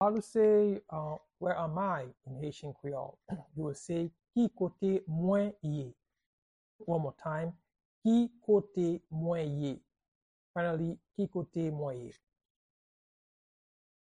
How-to-say-Where-am-I-in-Haitian-Creole-–-Ki-kote-mwen-ye-pronunciation-by-a-Haitian-teacher.mp3